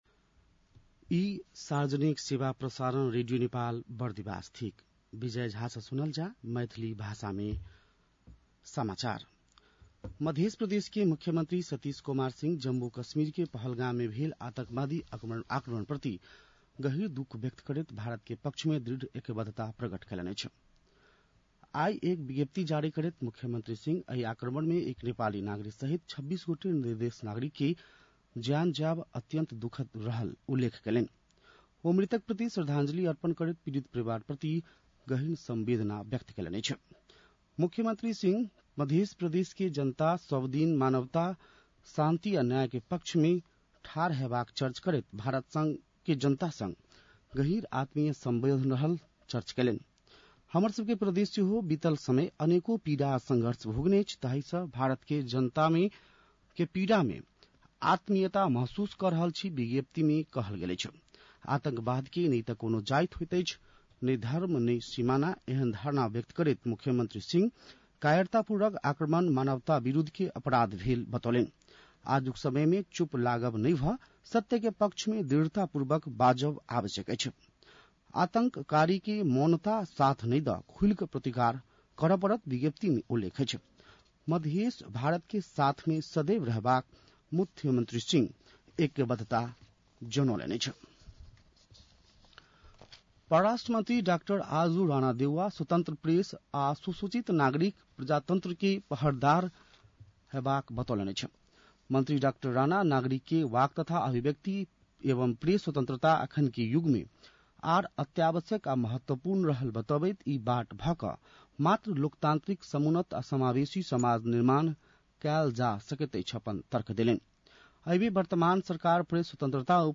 मैथिली भाषामा समाचार : २७ वैशाख , २०८२
6-PM-Maithali-News-1-27.mp3